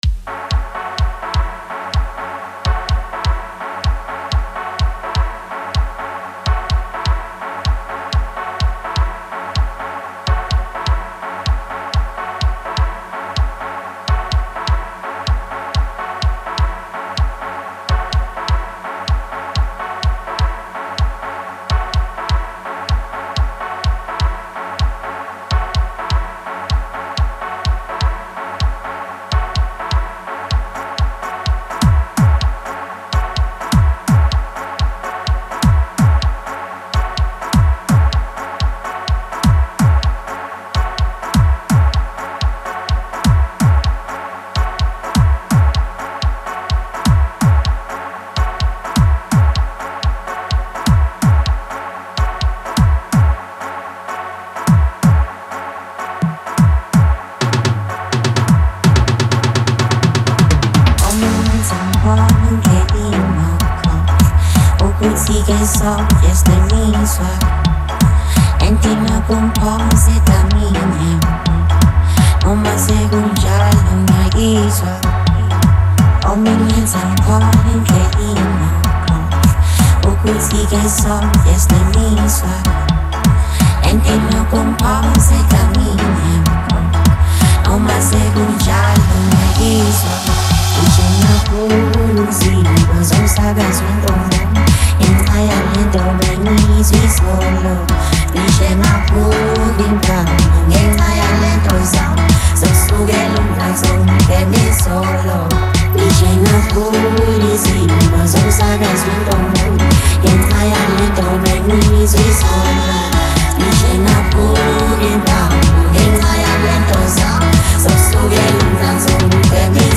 06:39 Genre : Gqom Size